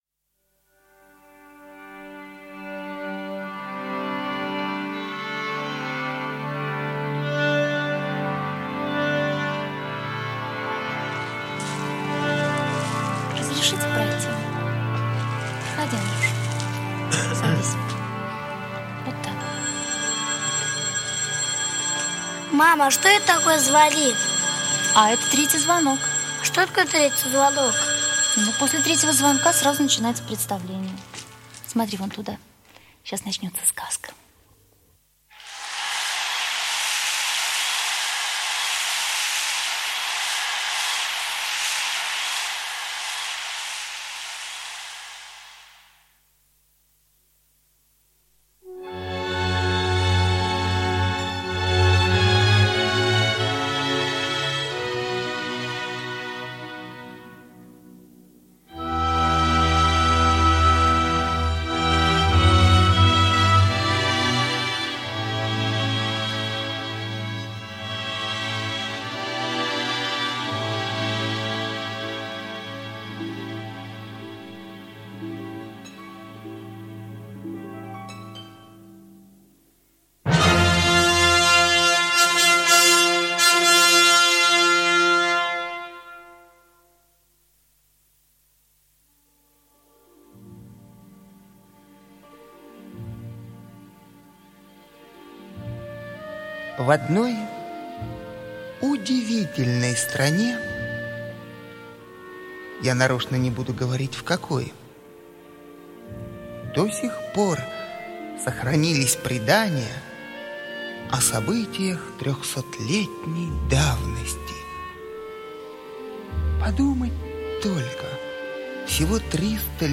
Спящая Красавица - музыкальная сказка Чайковского - слушать
Спящая Красавица - музыкальная сказка Чайковского П.И. Классическая музыка величайшего композитора для взрослых и детей.